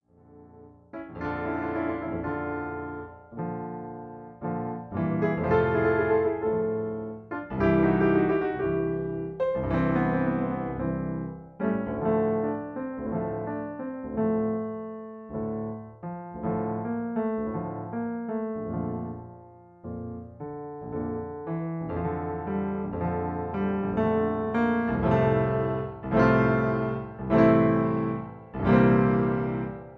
MP3 Piano Accompaniment